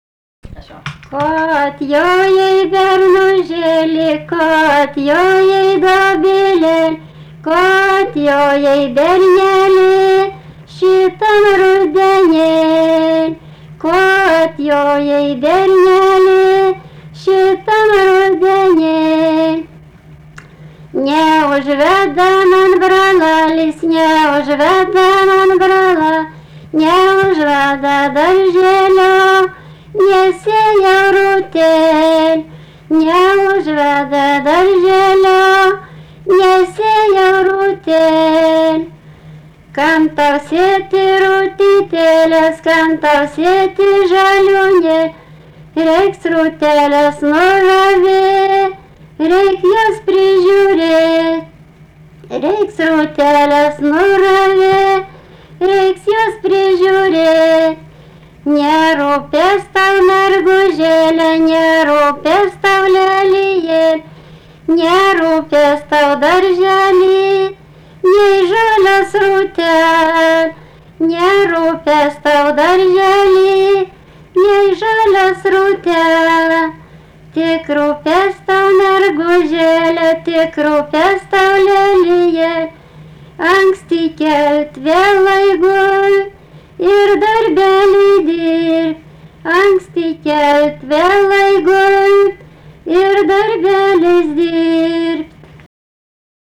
daina, vestuvių
Antašava
vokalinis